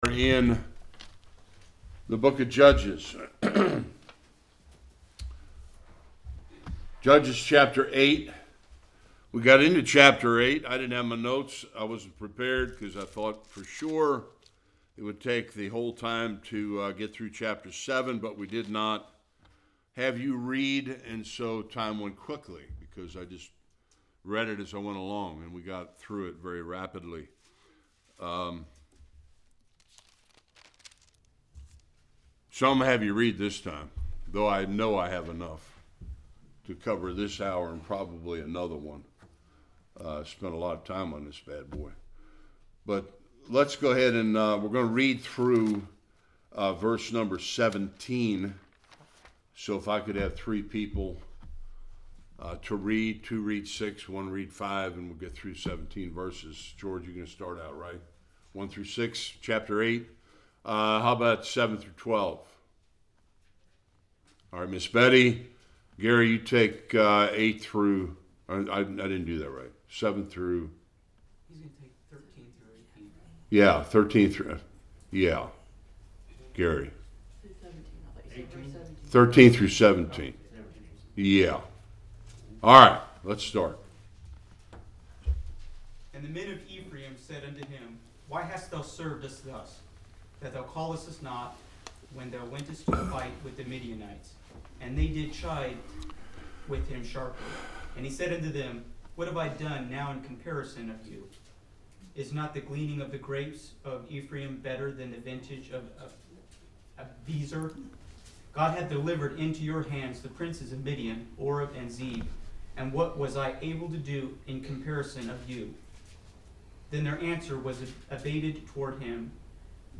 1-21 Service Type: Sunday School Gideon had some challenges ahead.